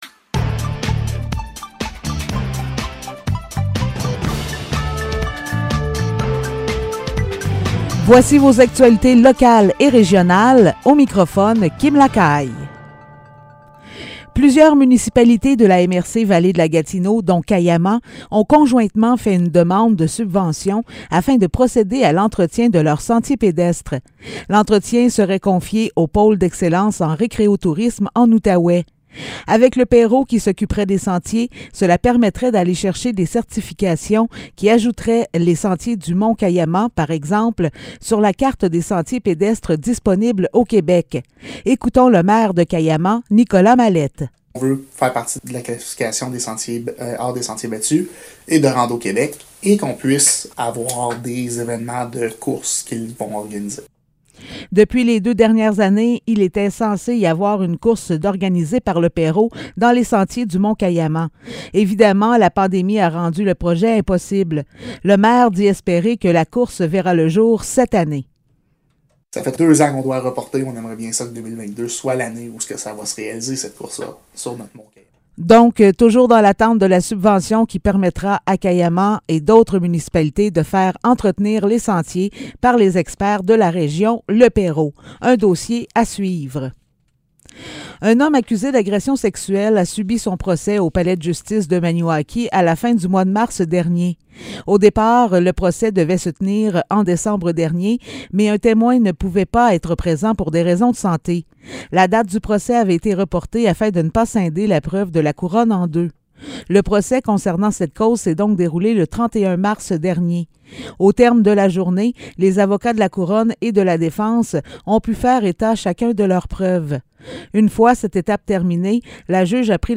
Nouvelles locales - 19 avril 2022 - 15 h